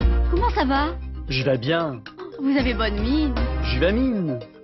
french old adverisment (loop)